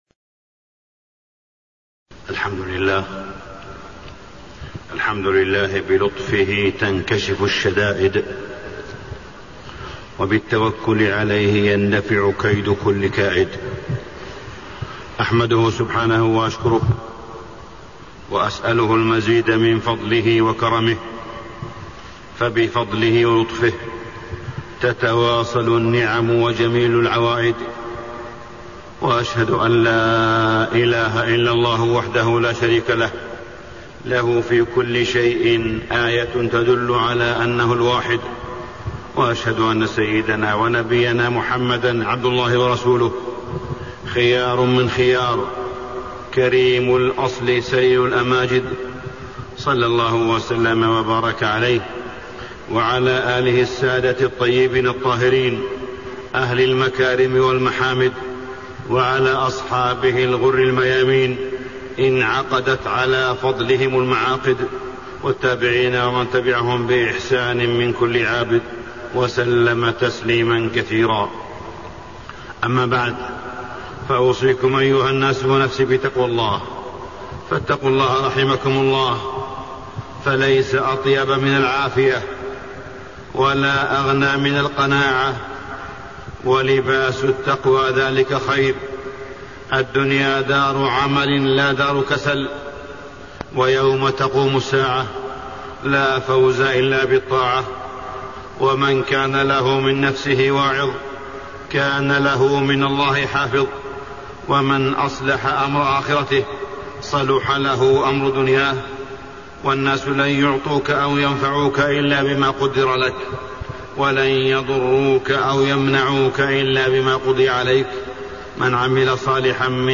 تاريخ النشر ٧ رجب ١٤٣٤ هـ المكان: المسجد الحرام الشيخ: معالي الشيخ أ.د. صالح بن عبدالله بن حميد معالي الشيخ أ.د. صالح بن عبدالله بن حميد الأدب والذوق الرفيع